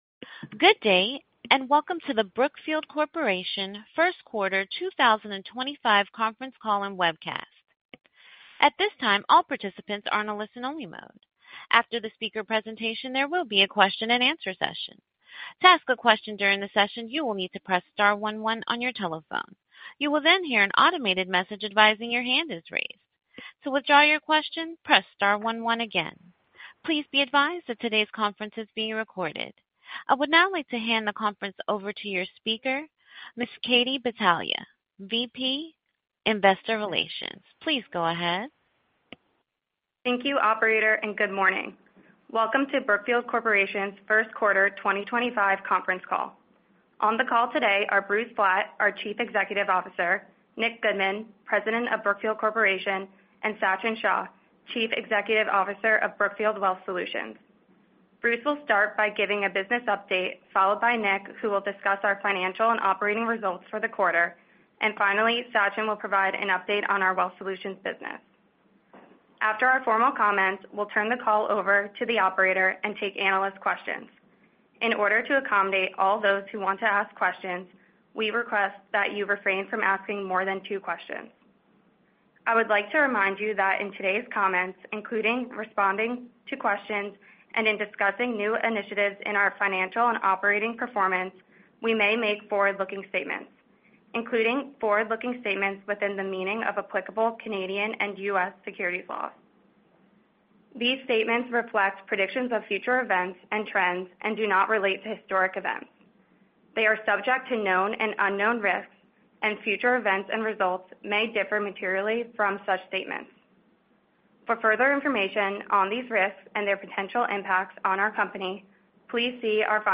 bn-q1-25-conference-call-and-webcast-new.mp3